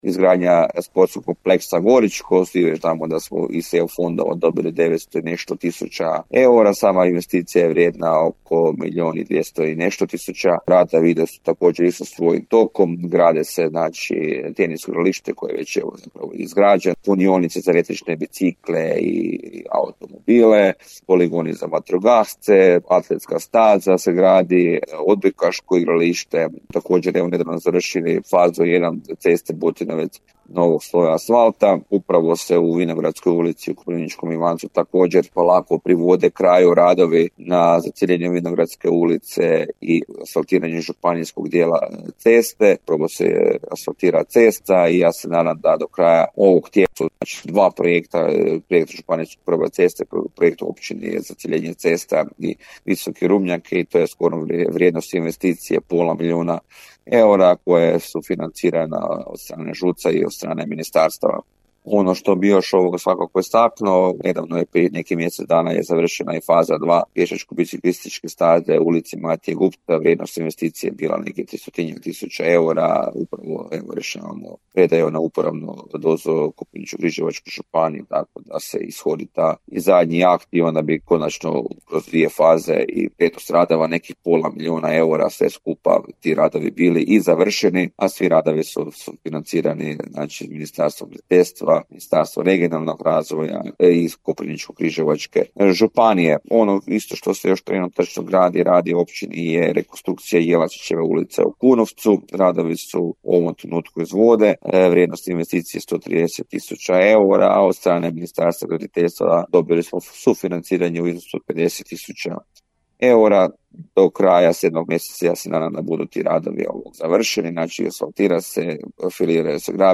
Gost emisije Susjedne općine u programu Podravskog radija je bio načelnik Općine Koprivnički Ivanec,